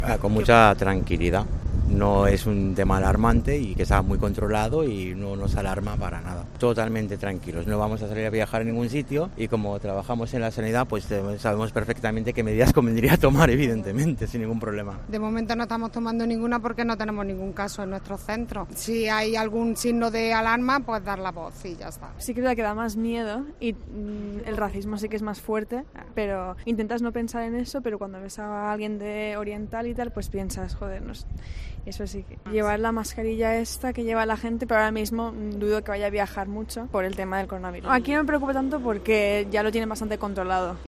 En la calle, mucha tranquilidad respecto al coronavirus. La gente cree que está informada y que lo tienen todo controlado. Han dicho en los micrófonos de Cope que no tienen previsto viajar y una chica ha reconocido que ha visto más racismo.